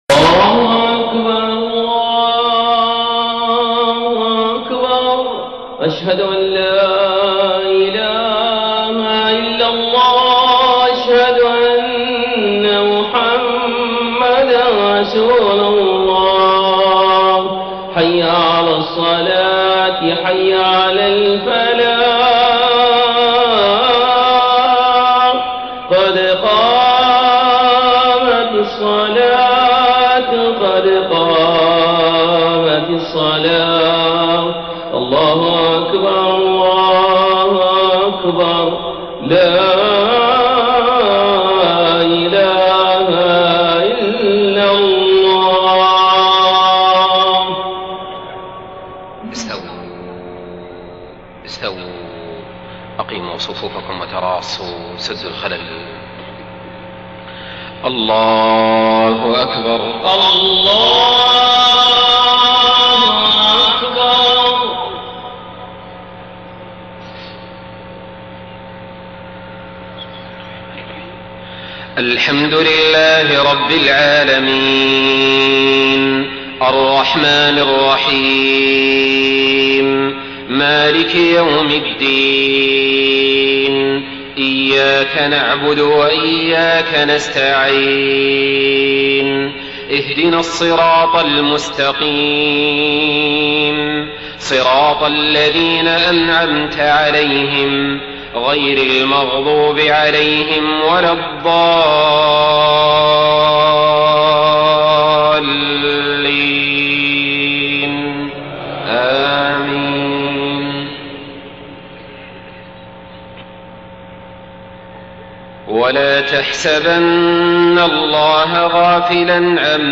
صلاة العشاء 5 صفر 1430هـ خواتيم سورة إبراهيم 42-52 > 1430 🕋 > الفروض - تلاوات الحرمين